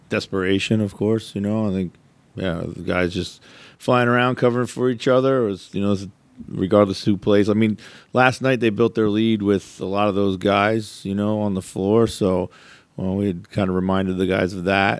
Timberwolves head coach Chris Finch says they played much better defense in this one, albeit against a short-handed Warriors team.